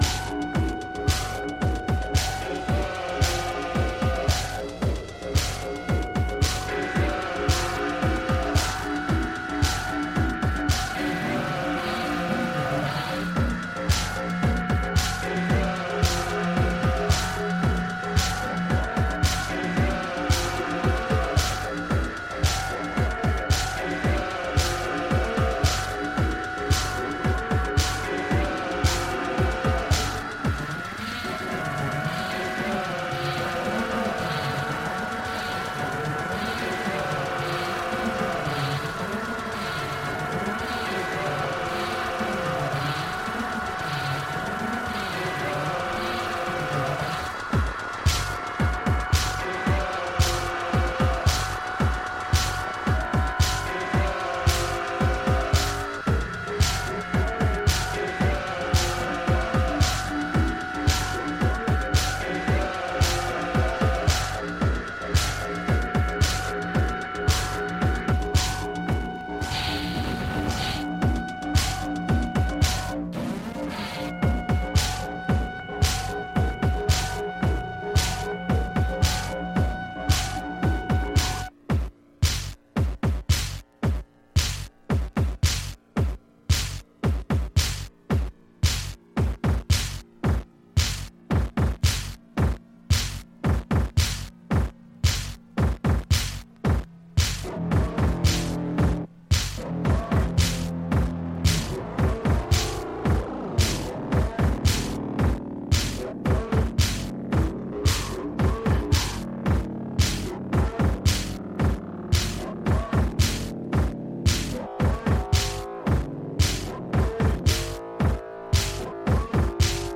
focused on direct, improvised methods.